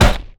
IMPACT_Generic_07_mono.wav